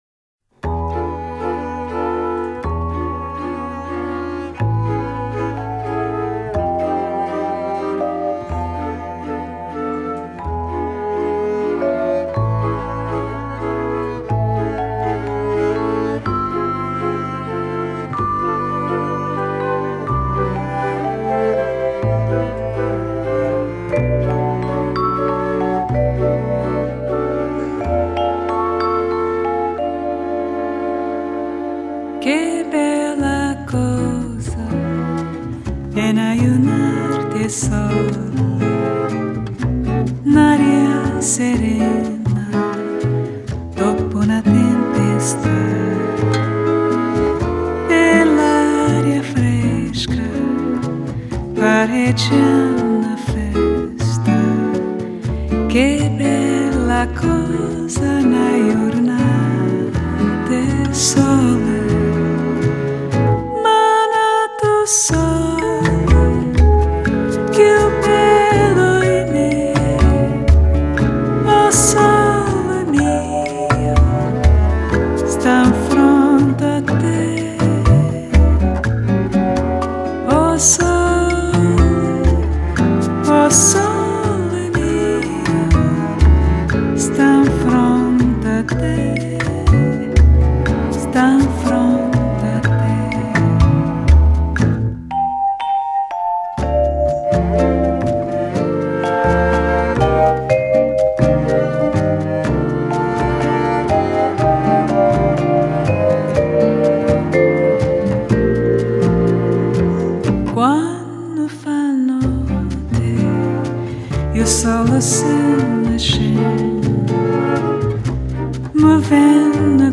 风格流派: Bossa Nova
从乐曲编排到吉他、萨克斯风、沙锤、钢琴等乐器的完美结合